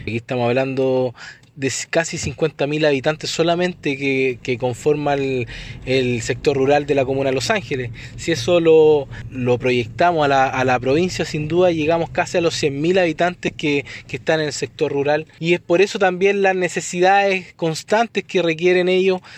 Por su parte, el gobernador de Bío Bío, Ignacio Fica, dijo que las problemáticas presentadas por los vecinos se condicen con la gran población que ellos representan en la provincia.